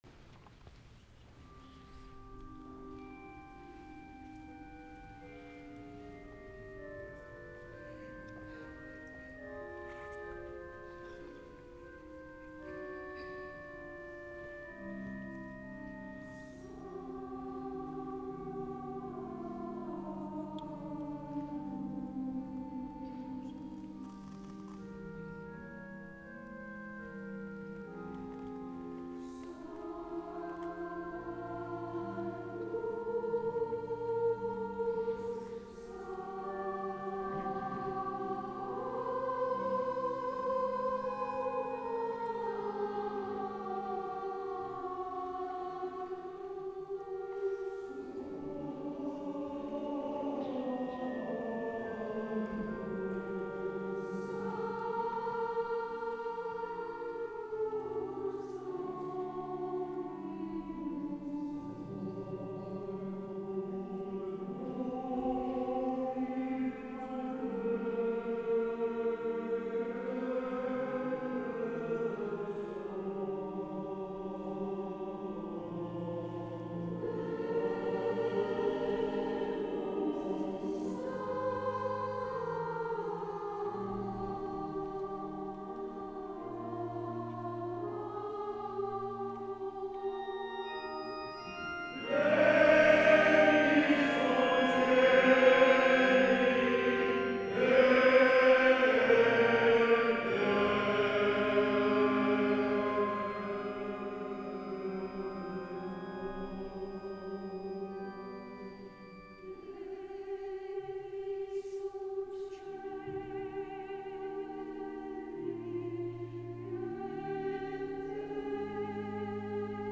S. Gaudenzio church choir Gambolo' (PV) Italy
25 aprile 2014  - La Corale ad Assisi
Santa Messa tra gli affreschi di Giotto
nella Basilica di San Francesco
MP3 binaural recording: